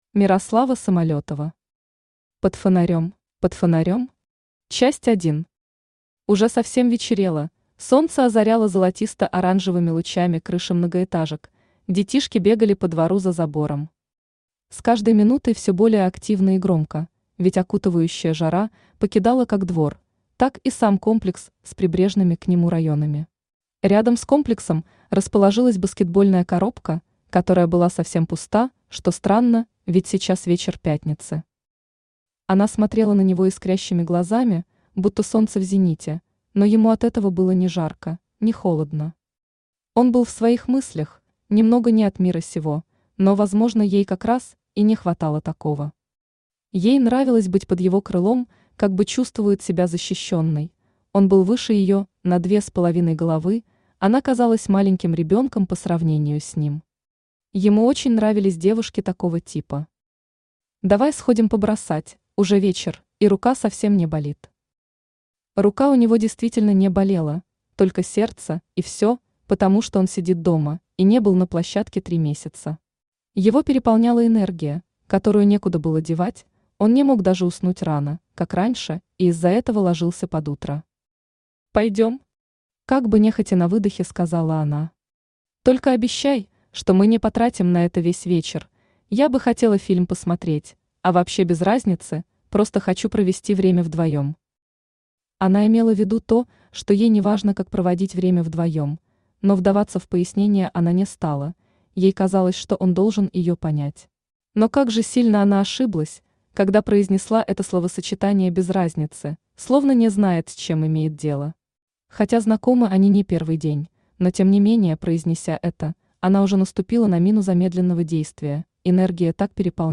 Aудиокнига Под фонарем Автор Мирослава Самолётова Читает аудиокнигу Авточтец ЛитРес.